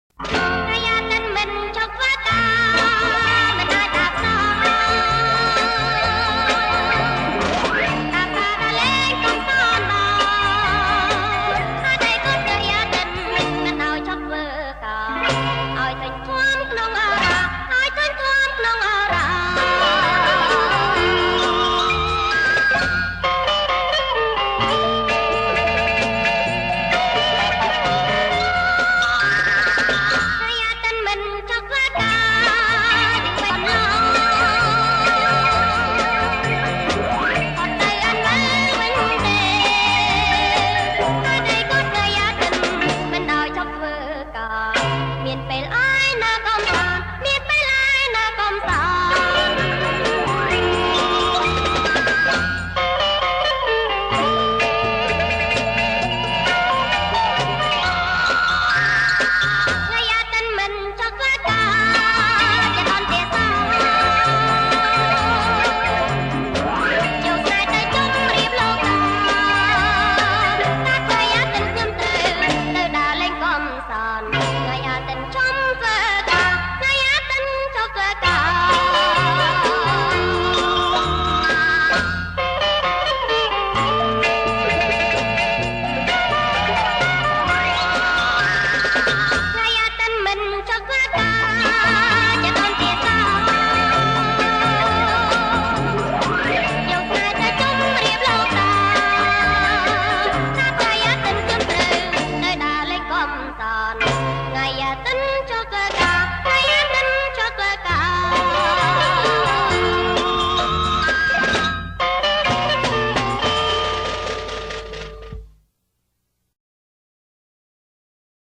• ប្រគំជាចង្វាក់ Jerk